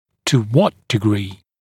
[tu wɔt dɪ’griː][ту уот ди’гри:]до какой степени